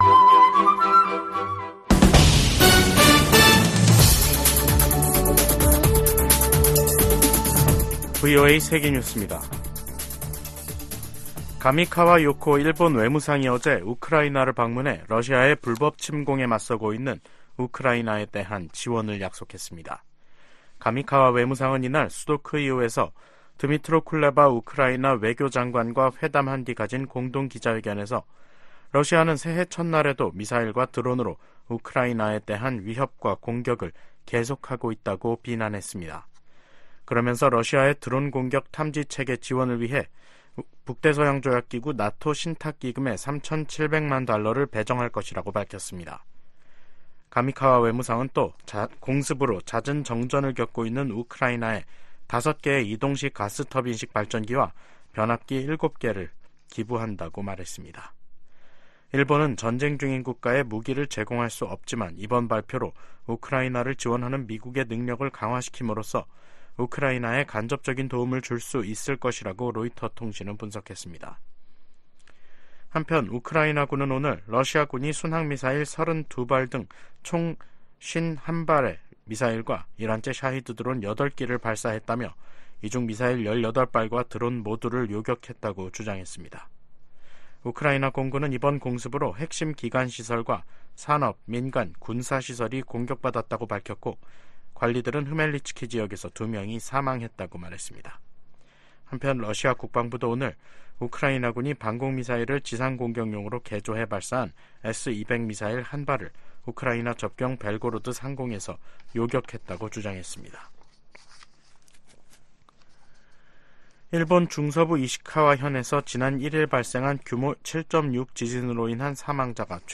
VOA 한국어 간판 뉴스 프로그램 '뉴스 투데이', 2024년 1월 8일 2부 방송입니다. 북한 군이 한국의 서북도서 인근에서 포 사격을 실시하자 한국 군도 해당 구역 군사훈련을 재개하기로 했습니다. 미 국무부는 북한의 서해 해상 사격에 도발 자제와 외교적 해결을 촉구했습니다. 미국 정부는 팔레스타인 무장정파 하마스가 북한 로켓 부품을 이용해 신무기를 만들고 있는 것과 관련해, 북한은 오래 전부터 중동 지역에 무기를 판매해 오고 있다고 밝혔습니다.